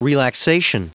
Prononciation du mot relaxation en anglais (fichier audio)
Prononciation du mot : relaxation